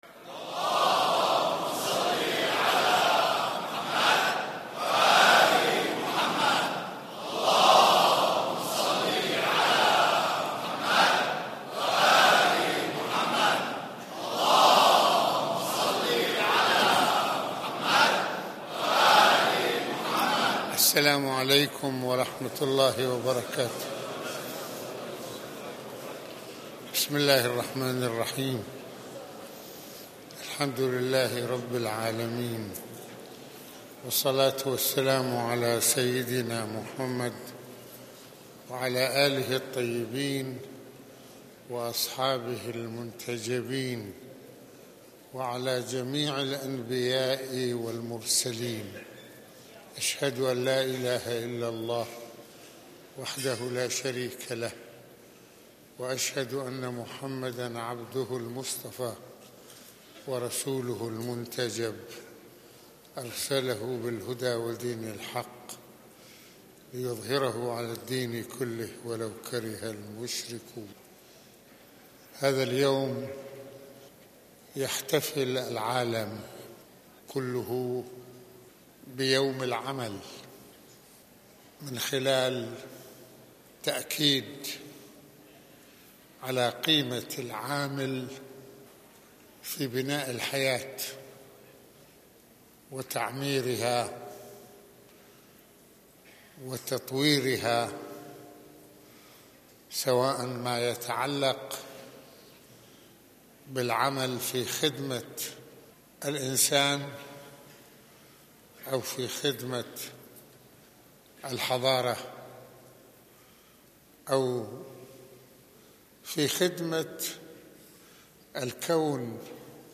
خطبة الجمعة
مسجد الامامين الحسنين